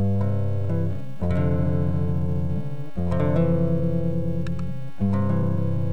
I attached a 6 second of guitar + noise.
Those red horizontal lines that continue throughout the track are the individual frequencies that make up the buzz.
Also, the waveform is not centred around the horizontal zero line - it is shifted significantly below the middle of the track.